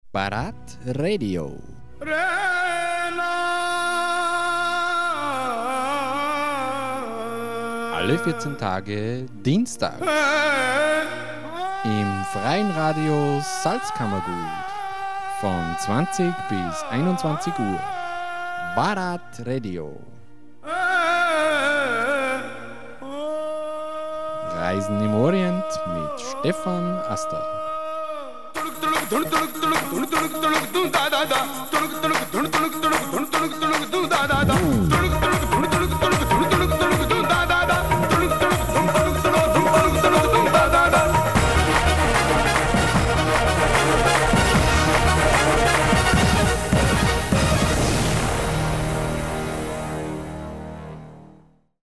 Sendungstrailer